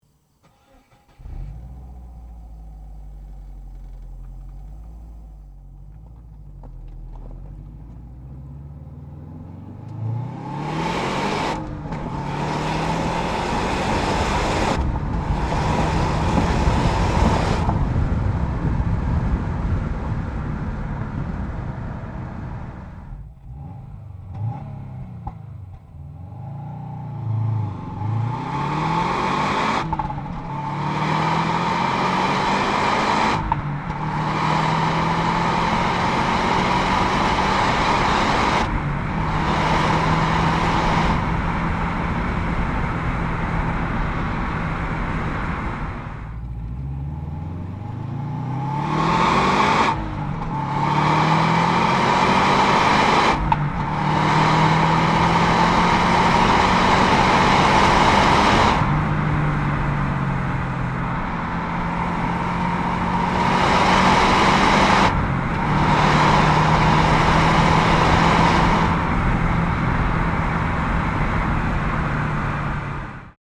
صدای اگزوز
2009-Subaru-Impreza-WRX.mp3